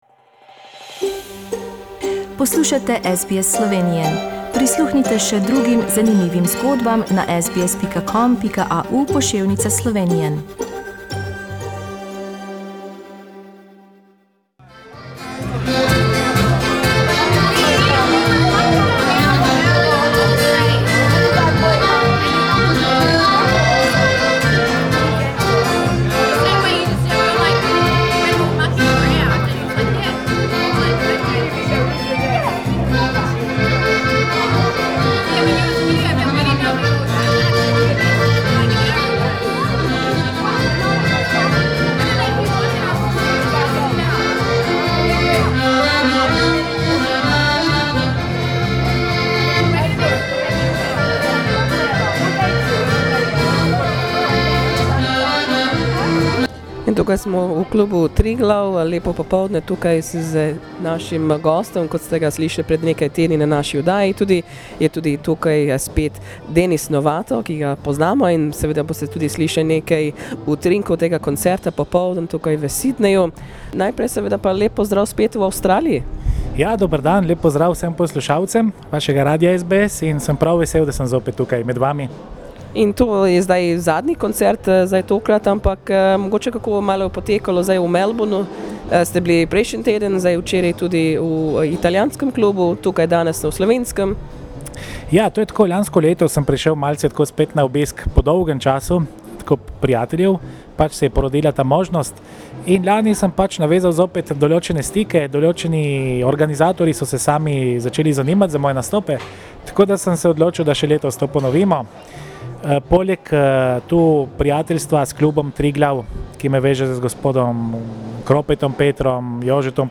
Pogovarjali smo se z njim v klubu Triglav v Sydneyu.